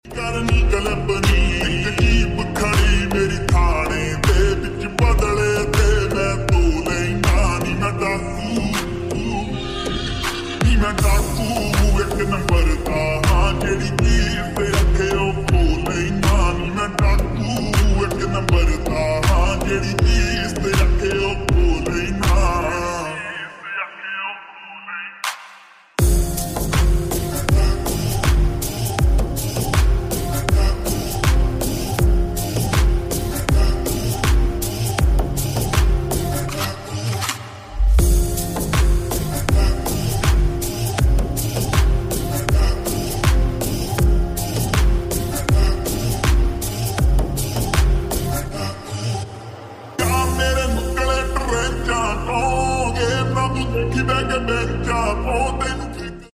Yamaha R15 V3 Jomthai Chain Sound Effects Free Download